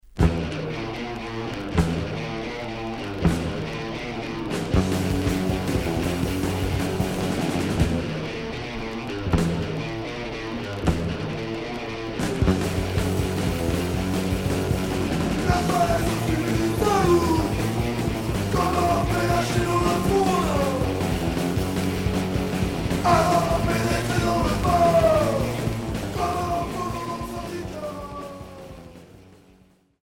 Hardcore Unique 45t